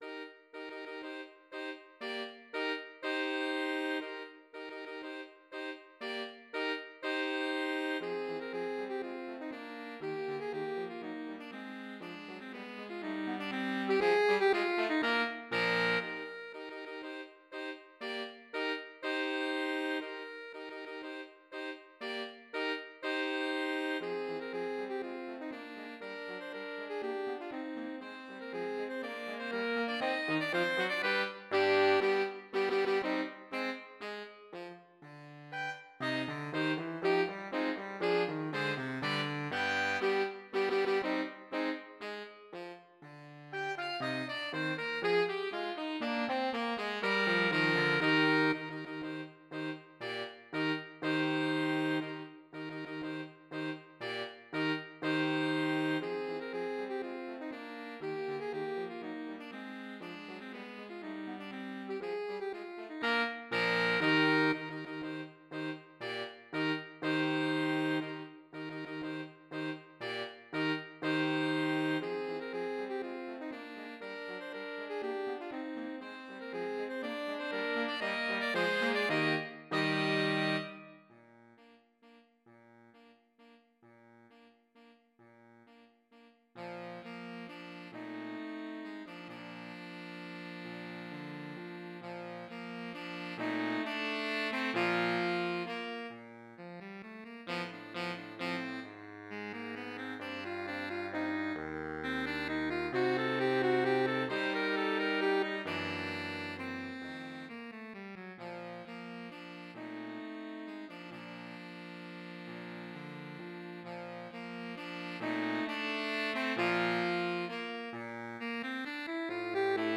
Voicing: 4 Sax